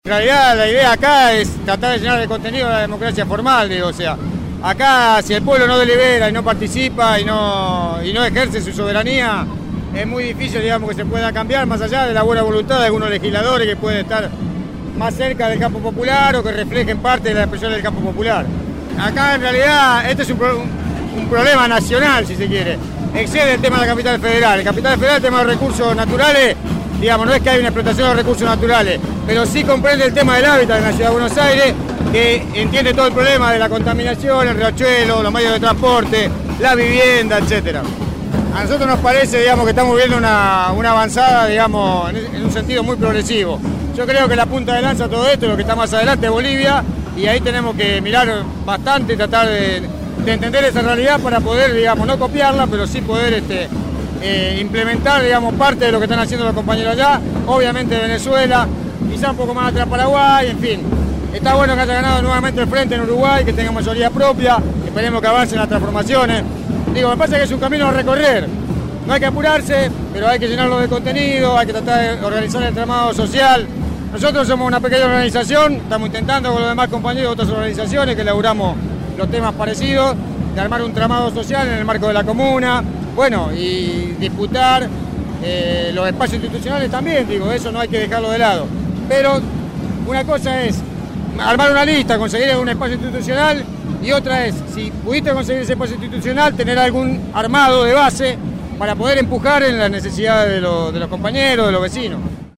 Voces de la Marcha 2 El 17 de diciembre de 2009 Asambleas barriales y sindicatos en la presentación de las Campañas Públicas frente al Congreso Nacional en el calor de diciembre.